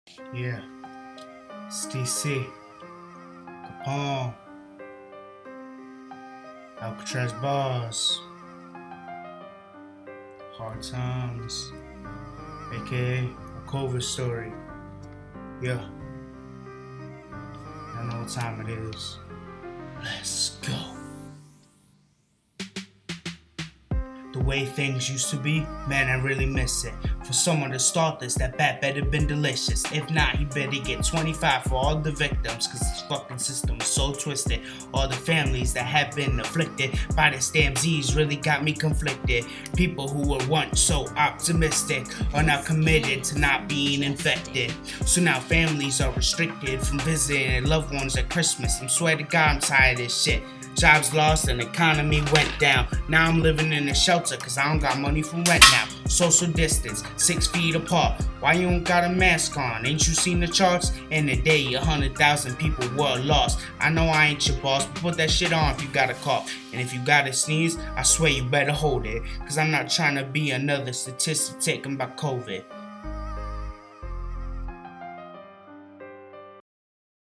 In this rap composition, Participant 189 shares fears over contracting the COVID-19 virus and calls on people to do their part in protecting the public. The participant explains challenges they faced during the pandemic including homelessness.